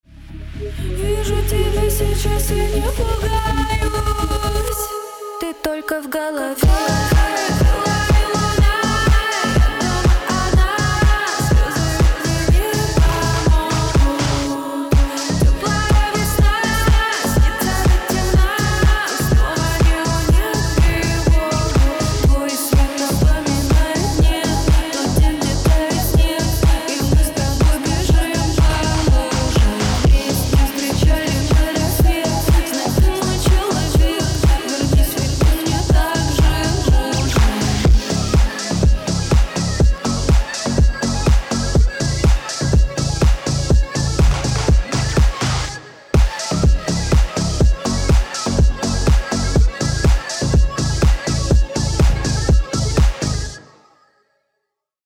• Качество: 320, Stereo
атмосферные